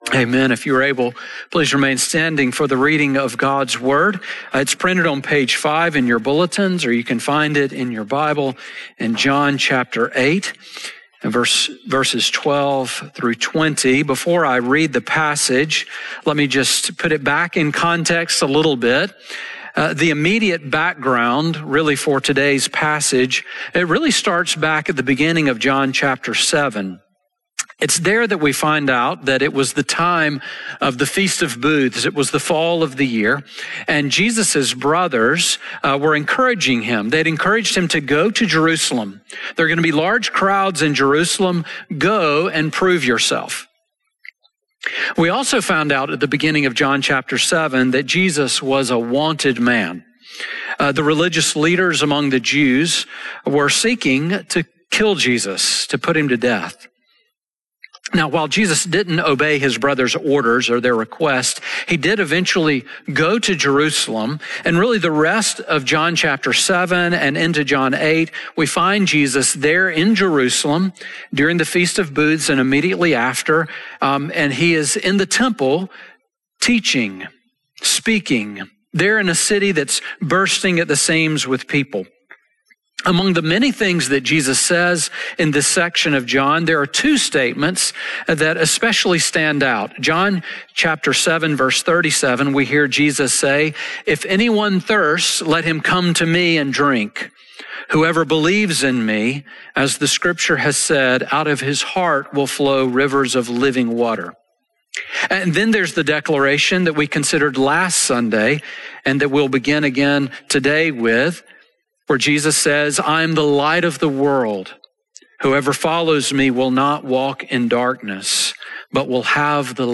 Sermon on John 8:12-20 from December 28